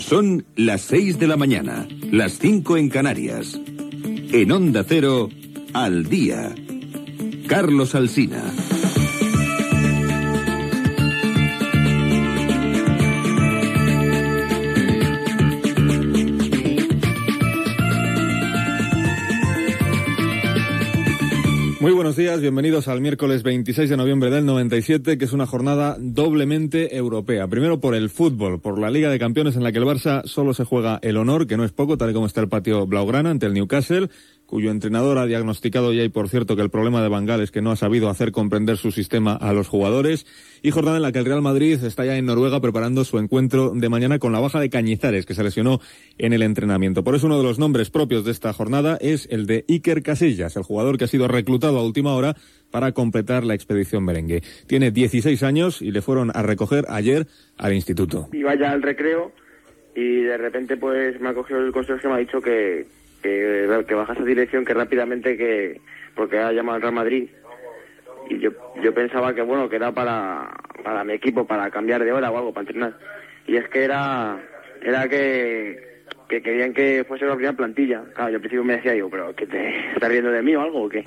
Hora, careta del programa, data, notícies de futbol i declaracions del porter del Real Madrid Iker Casillas, que té 16 anys.
Informatiu